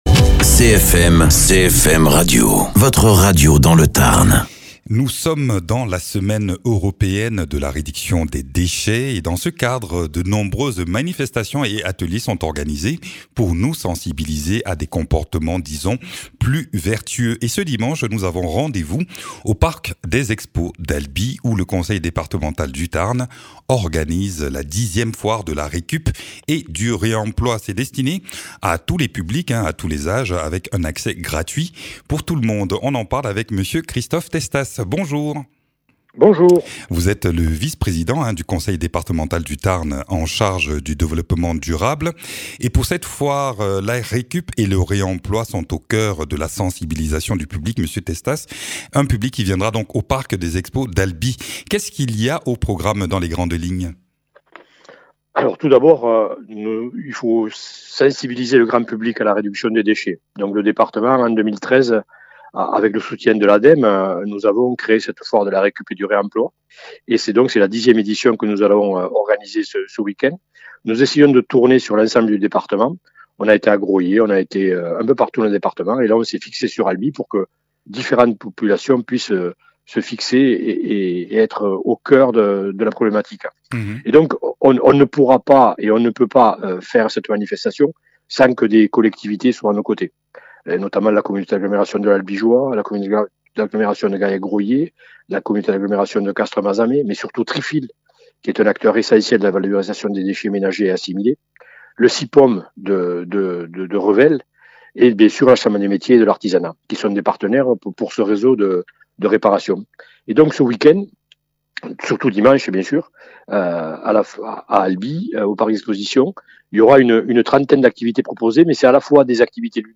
Interviews
Invité(s) : Christophe Testas, Vice-Président du Conseil Départemental du Tarn chargé du développement durable